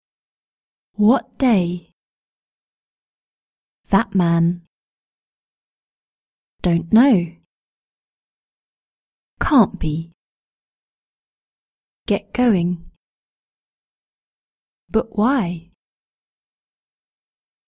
british-english-british-accent-glottal-stop-3.mp3